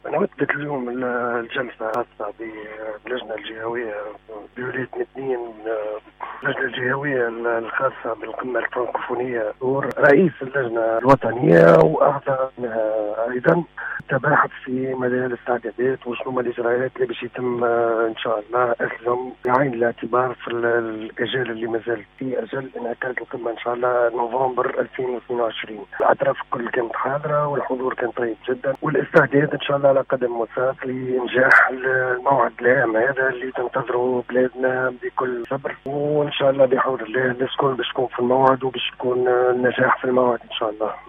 والي مدنين سعيد بن زايد يتحدث لأوليس أف أم .( تسجيل) . 0 نشر نشر تويت